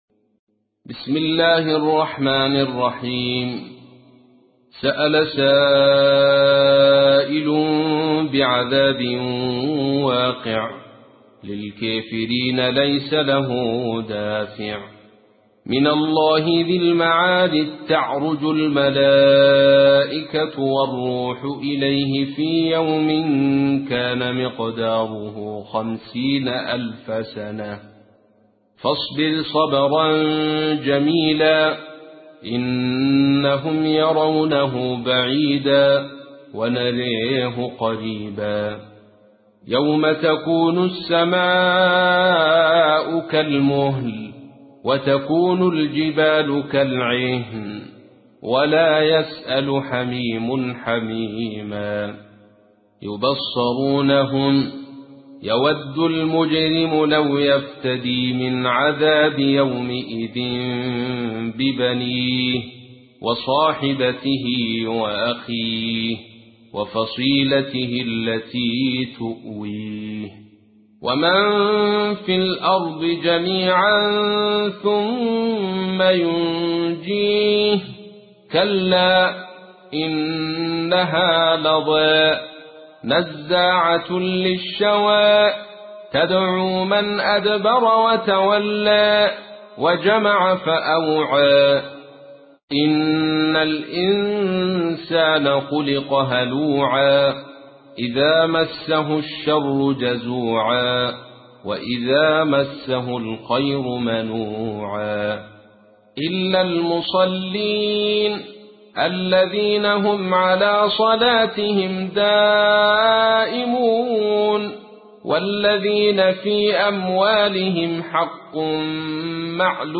تحميل : 70. سورة المعارج / القارئ عبد الرشيد صوفي / القرآن الكريم / موقع يا حسين